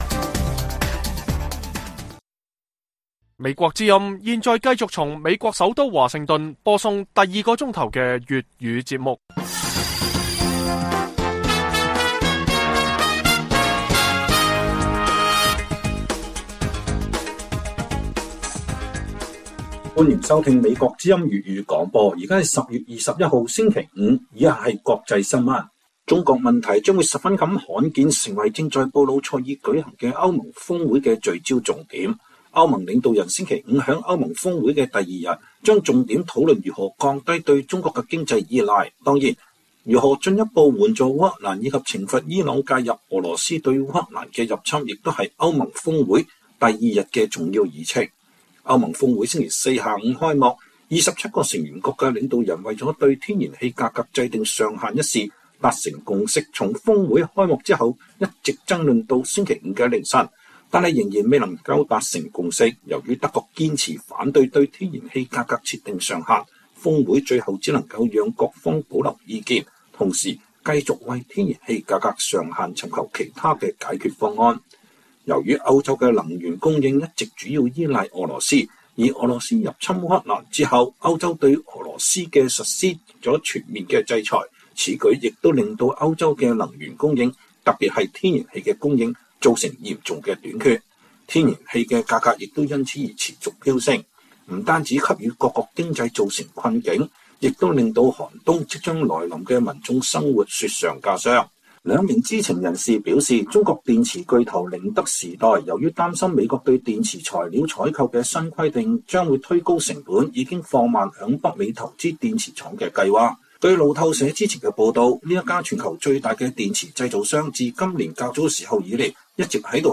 粵語新聞 晚上10-11點 : 越來越多人擔心國會換屆後美援烏力度將減弱 白宮發言人堅稱烏克蘭得到兩黨有力支持